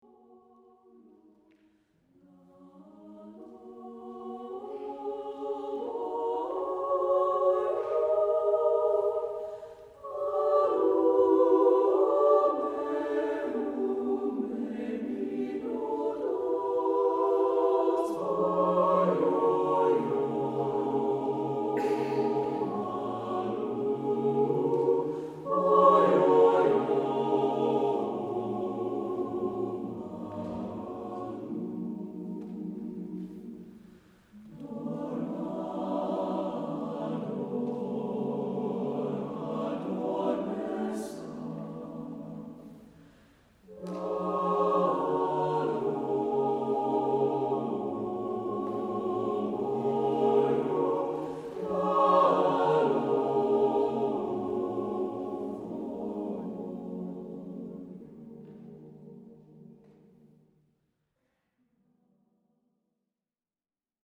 chorus (SATB)